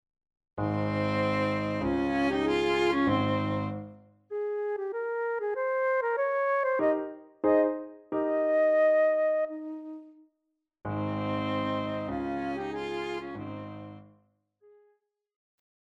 Classical
Flute,Piano,Cello
Chamber
Trio